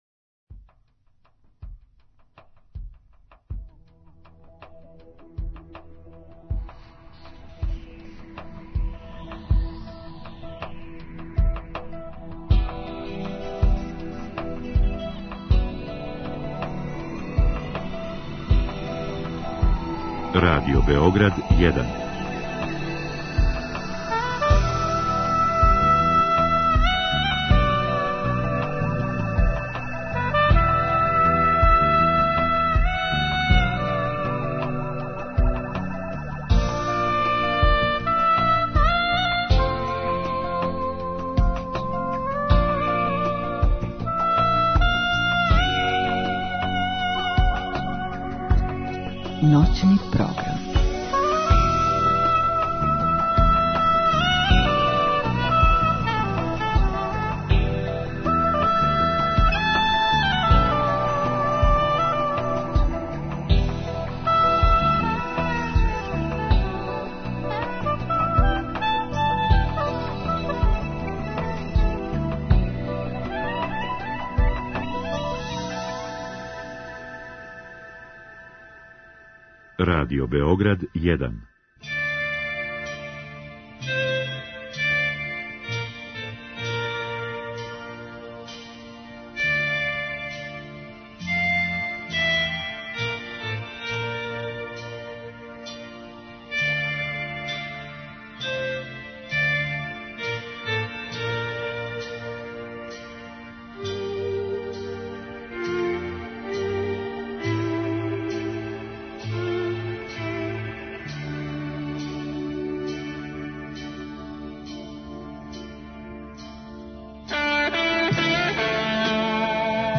Гост: Ђорђе Давид,глумац и музичар
У Малим ноћним причама имаћемо прилику да чујемо песме са овог албума, да сазнамо зашто је то најкомплетнија прича у његовој каријери и да ли је нови албум продукцијски нешто најлепше што је направио до сада.